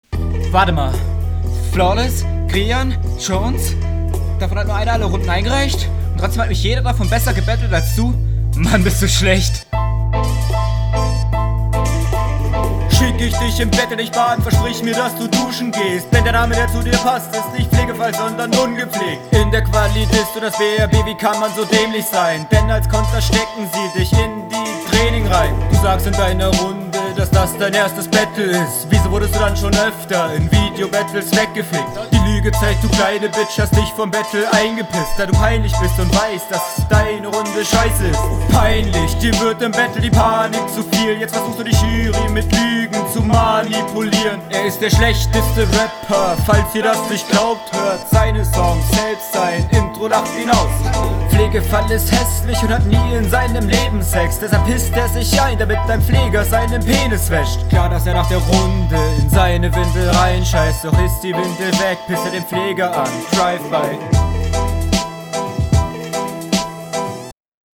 gute technik, flow stabil mit vielen guten variationen, delivery passt zum beat - gechillt. punchlinetechnisch …